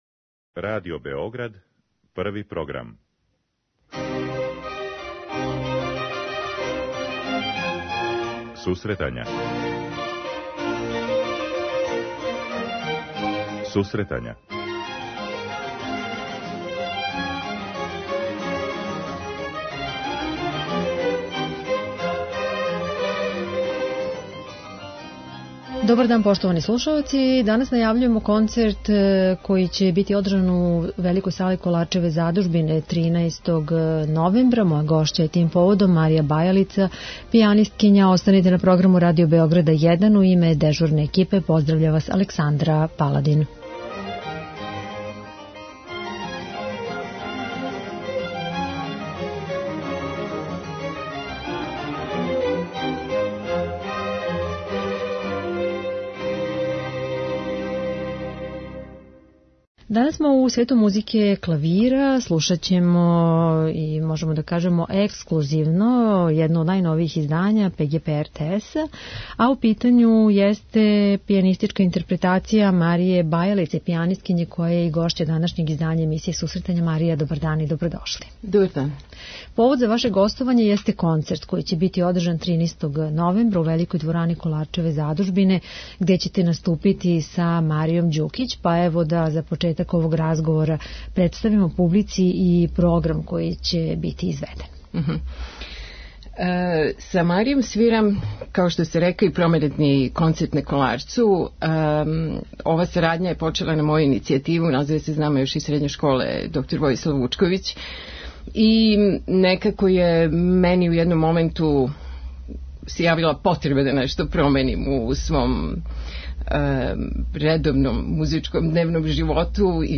а током разговора биће емитована и музика са новог ЦД-а ове уметнице